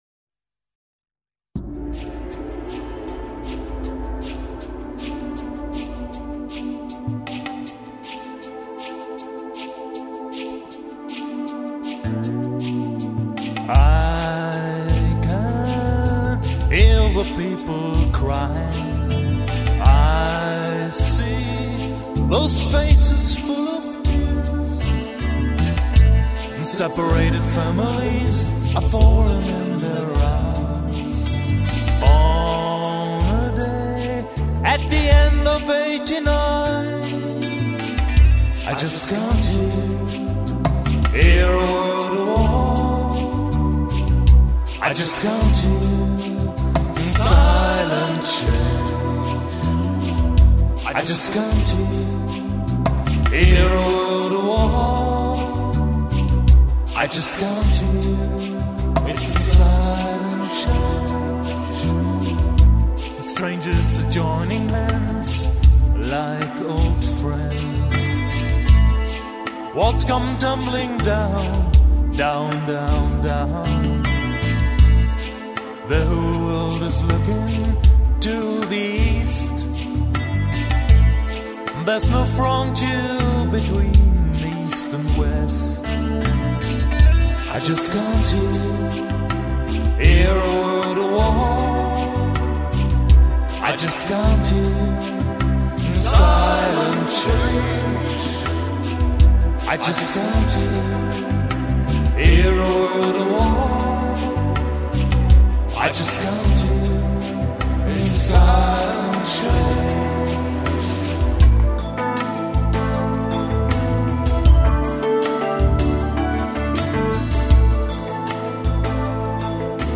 aufgenommen und abgemischt im eigenem
Eine melodiöse Pop-Rock-Produktion.
rockigen und folkigen Gitarrenelementen.